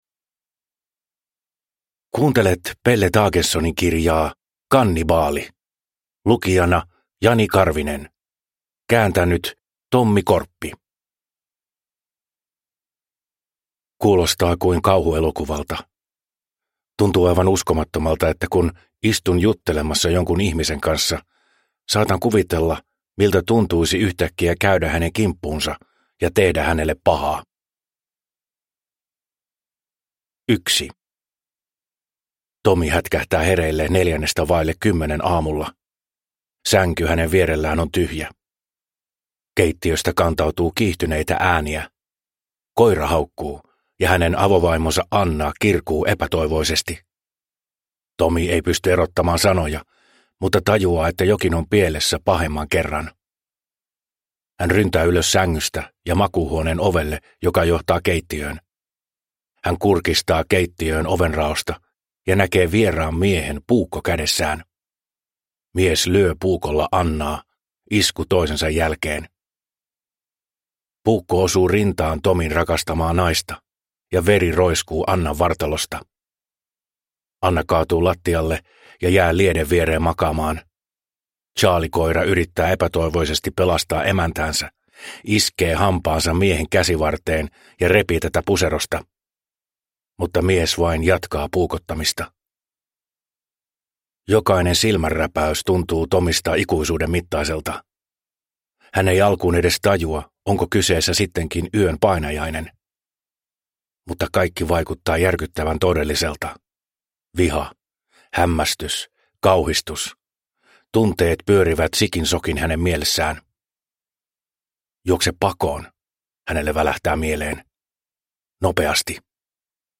Kannibaali – Ljudbok – Laddas ner